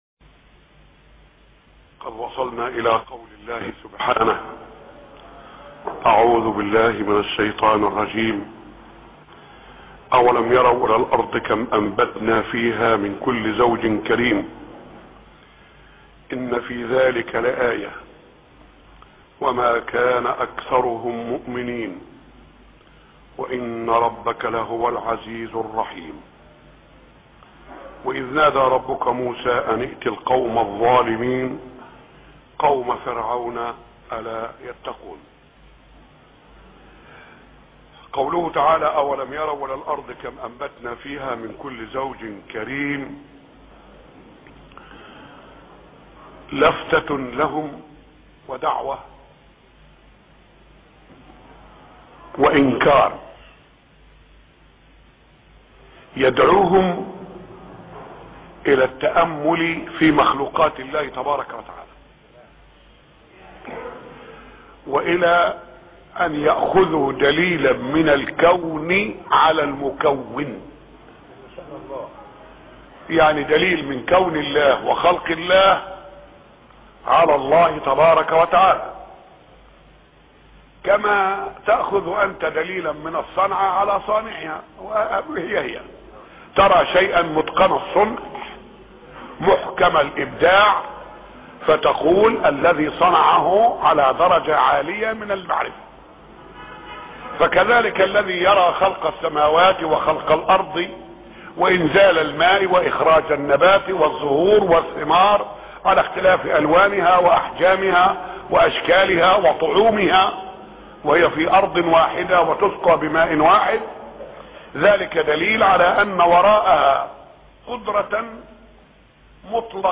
موضوع: سورة الشعراء - مسجد ر.شحاته الشعراء 1-6